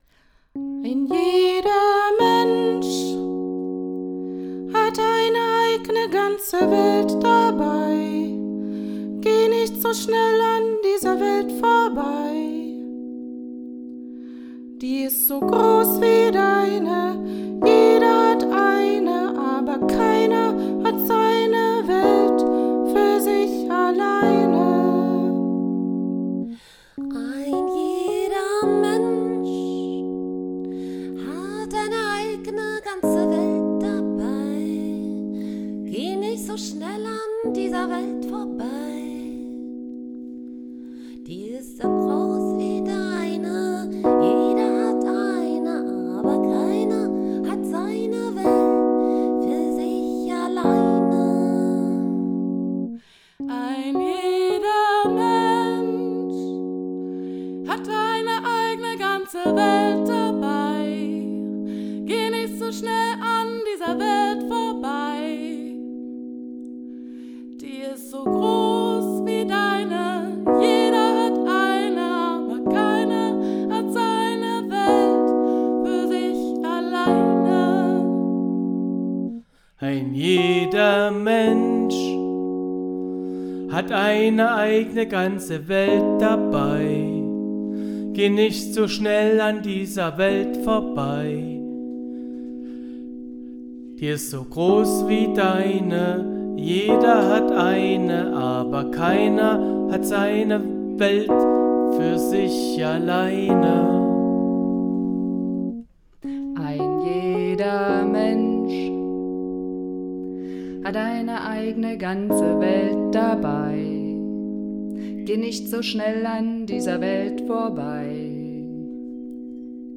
Das (kurze 2, min) Lange Lied (6 singers)